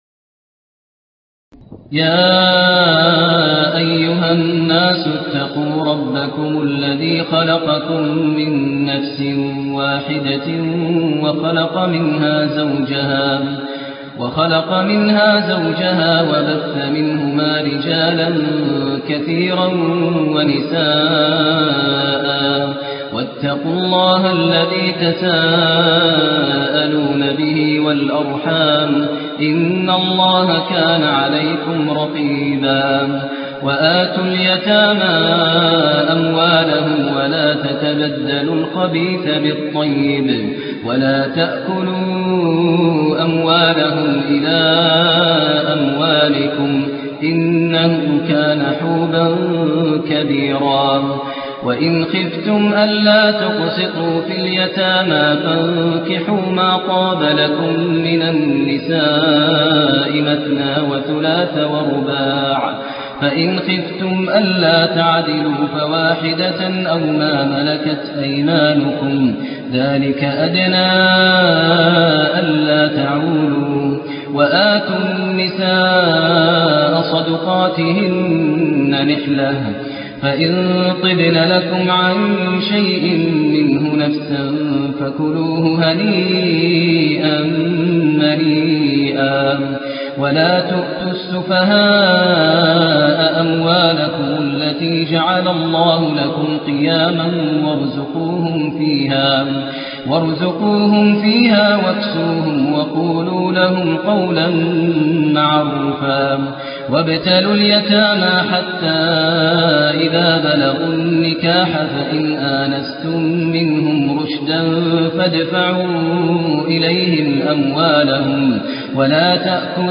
تهجد ليلة 24 رمضان 1427هـ من سورة النساء (1-57) Tahajjud 24 st night Ramadan 1427H from Surah An-Nisaa > تراويح الحرم النبوي عام 1427 🕌 > التراويح - تلاوات الحرمين